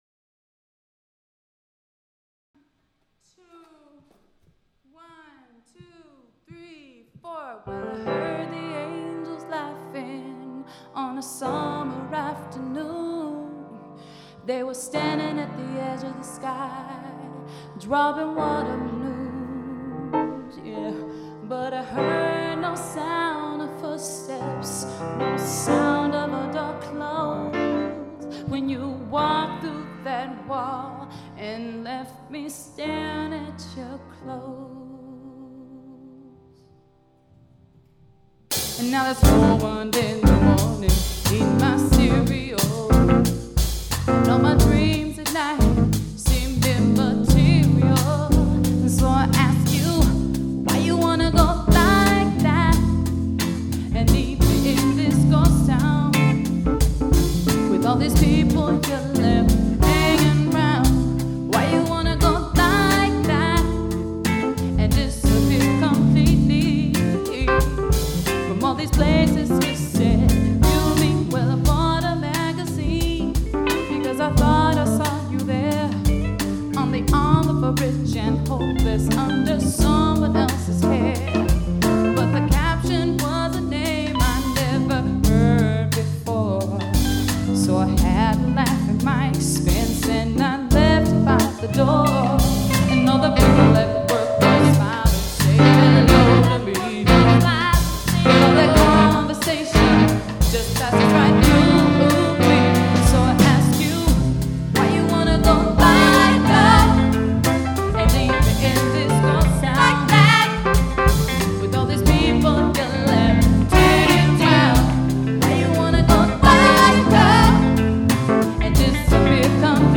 Live Recording 9/25/98